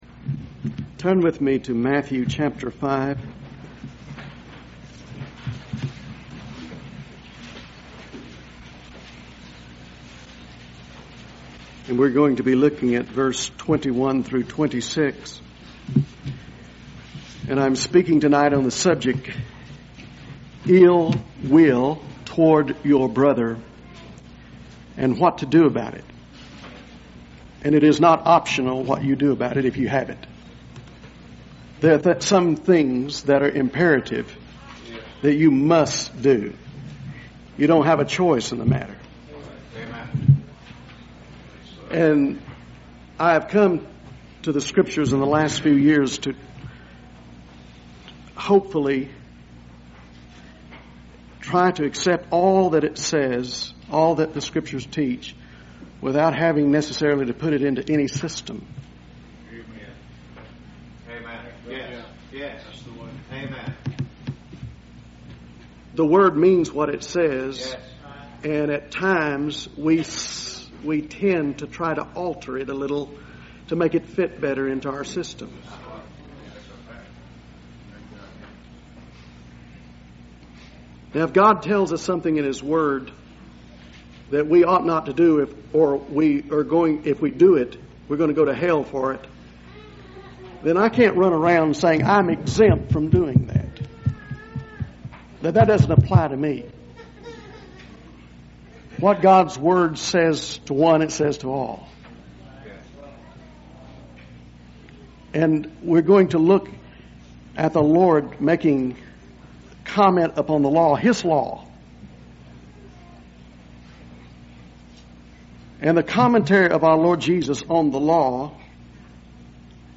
I was helped by this sermon.